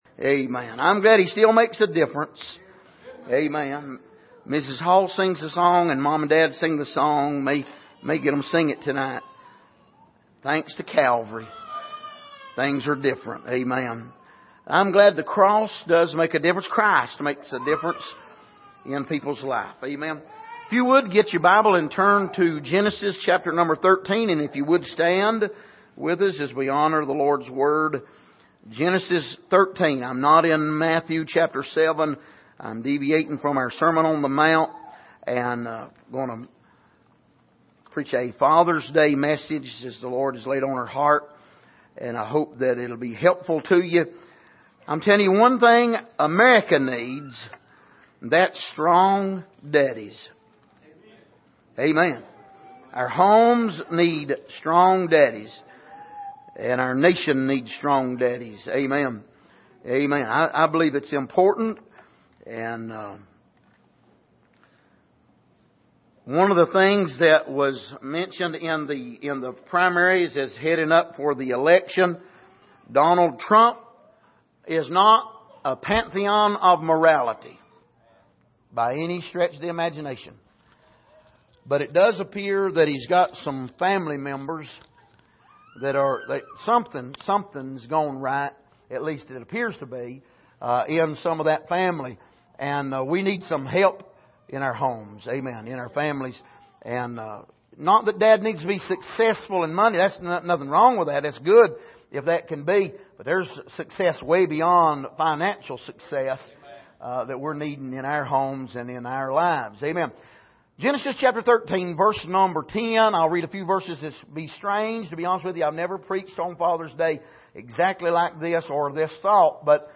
Passage: Genesis 13:10-13 Service: Sunday Morning